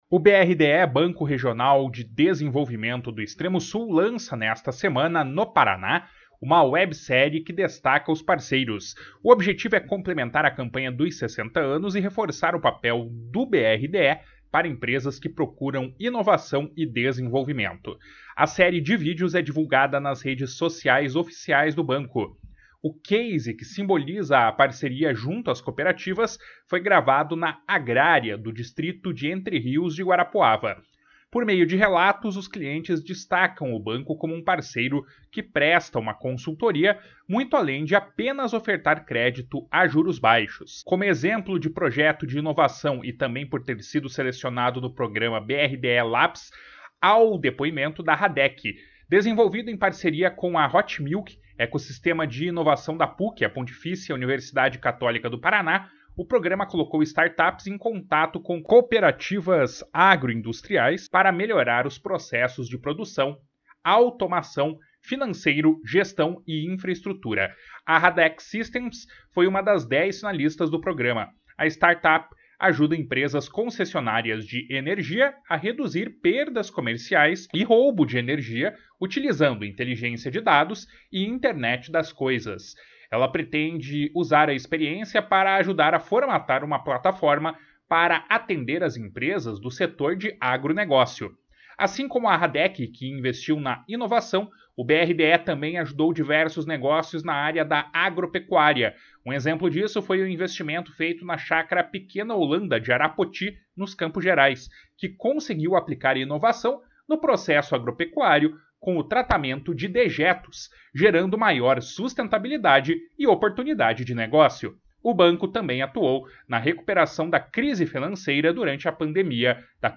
Por meio de relatos, os clientes destacam o banco como um parceiro que presta uma consultoria, muito além de apenas ofertar crédito a juros baixos.